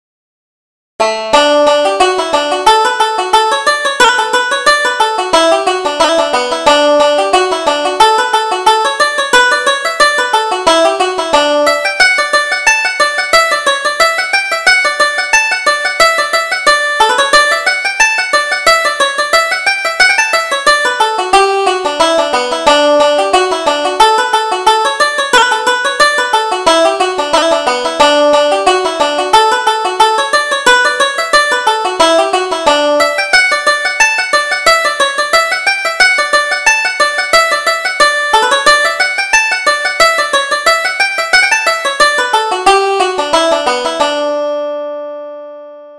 Reel: Clarkson's Reel